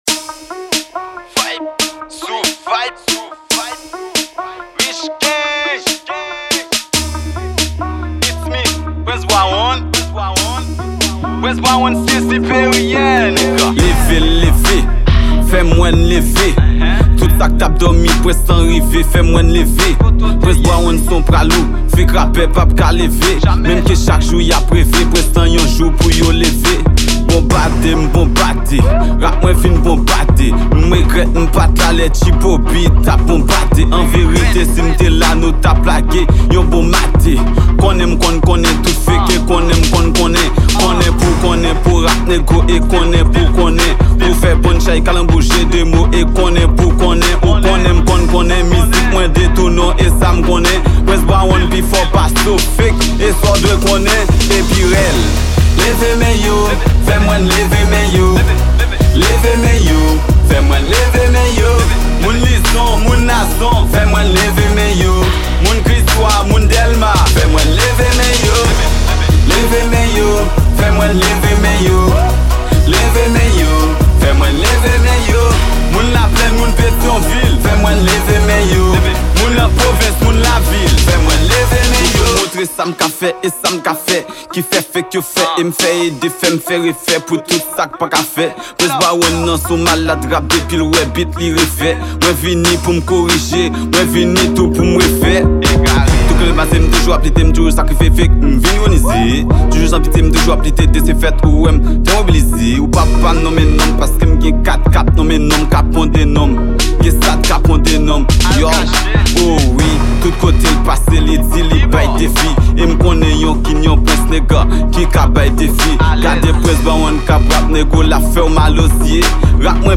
Genre: RAAP.